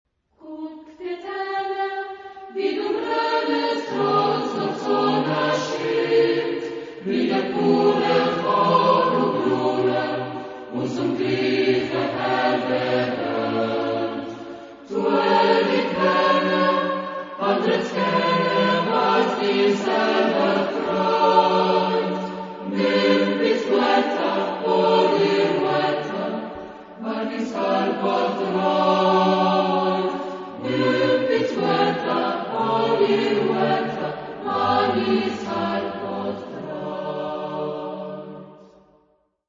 Carácter de la pieza : optimista ; feliz
Tipo de formación coral: SAB O STB  (3 voces Coro mixto )
Tonalidad : do (centro tonal)